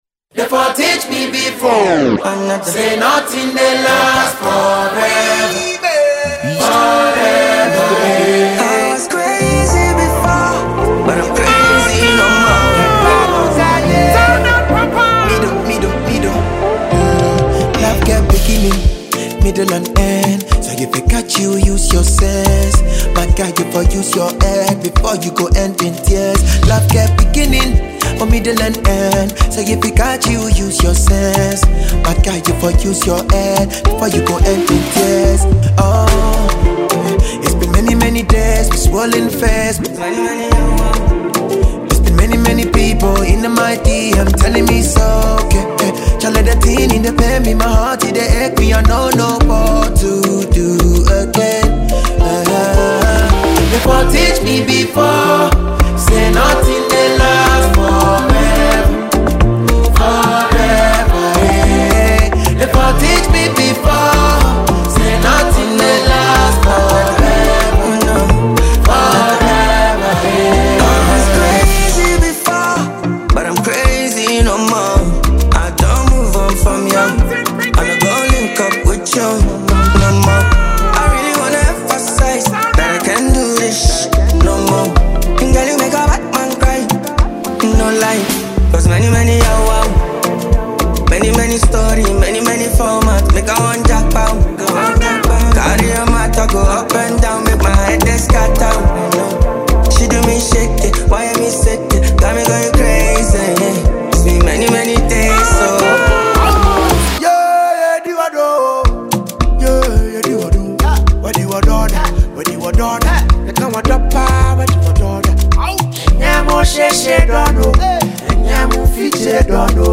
This is a banger all day.